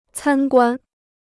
参观 (cān guān) พจนานุกรมจีนฟรี